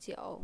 描述：这是一个乡村小镇的小餐馆。很多沃拉和喋喋不休。 记录：Sound Devices 702T，Sanken CS1e
Tag: 酒馆 喋喋不休 氛围 聊天 气氛 餐馆 熟食店 人群 酒吧 烧烤 沃拉